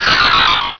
cries
-Replaced the Gen. 1 to 3 cries with BW2 rips.
snorunt.aif